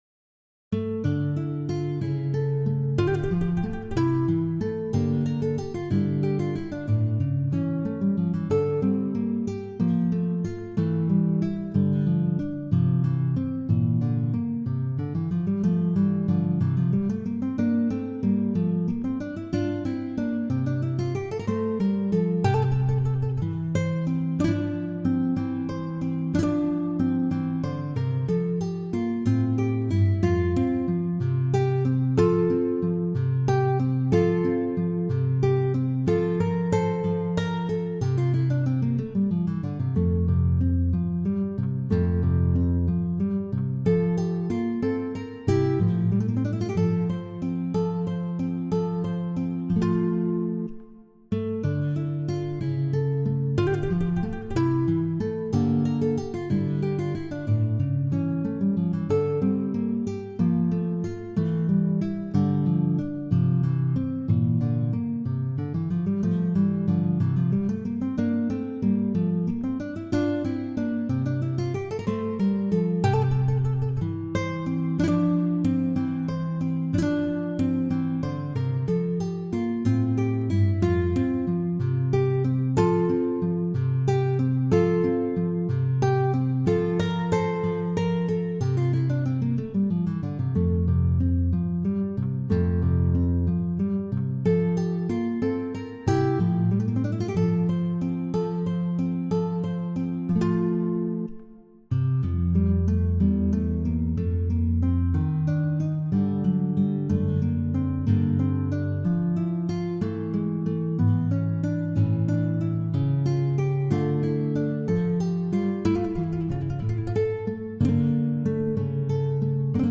Sonate en ré mineur (K9).pdf
Sonate-en-re-mineur-K9.mp3